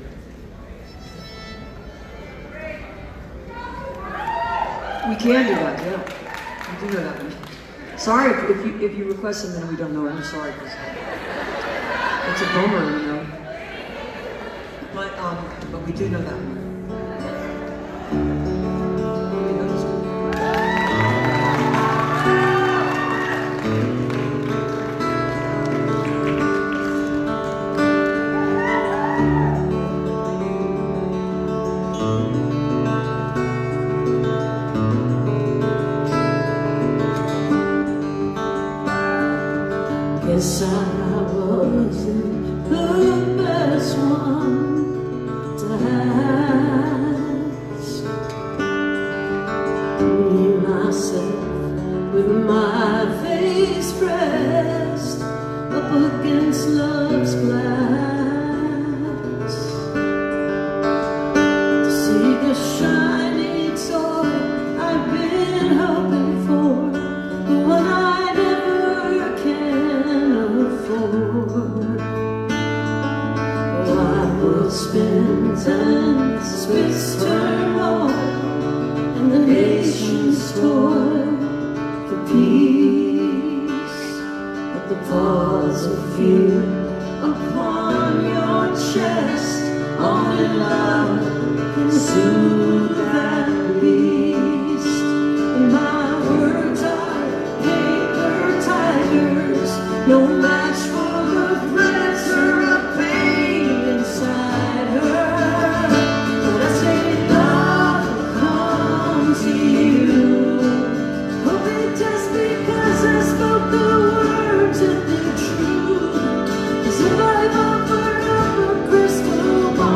(captured from facebook live streams)